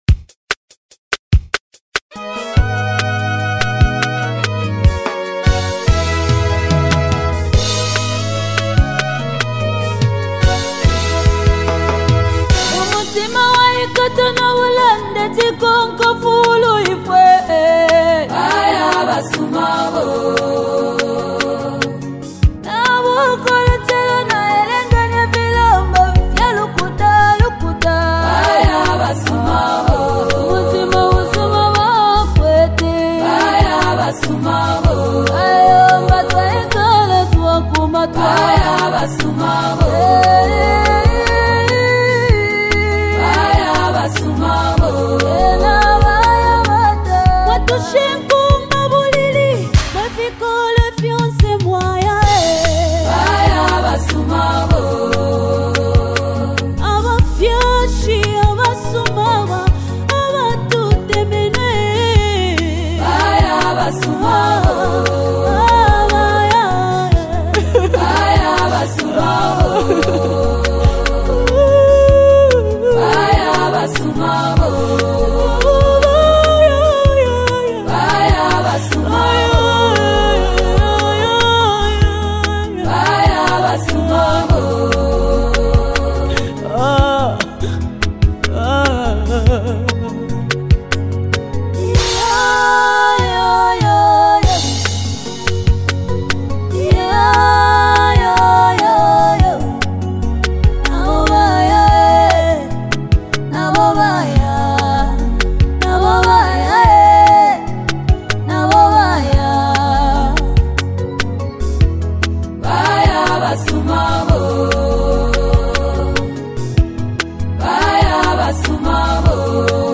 delivers a soul-stirring WORSHIP SONG